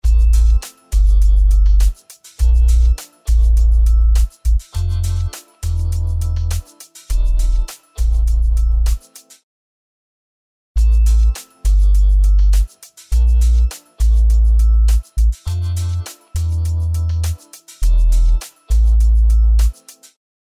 Tahle basa je ta nejzakladnejsi analogova hluboka basa, je tak klasicka ze snad uni ani neni co obdivovat, i kdyz jeji kouzlo se rozhodne nezapre:)) !
Takze mp3 ukazka je tady: 1. sekvence hraje TC Mercury, 2. sekvence hraje Discovery.
Na prilozeny mp3 vychazi na subbasu malinko lip Discovery je presnejsi na vsech notach viz obrazek uplne dole.
Mercury ma pomerne neukazneny noty vedle vzornyho Discovery, oba ale hraji supr! :D